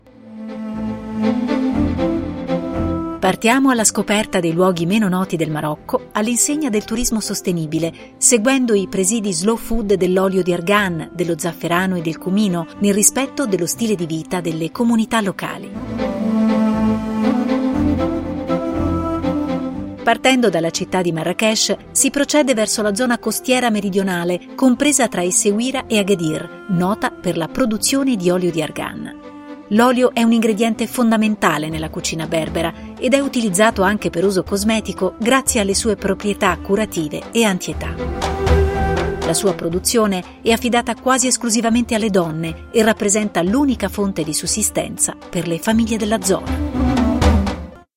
Giornalista speaker- Voice talent
Sprechprobe: eLearning (Muttersprache):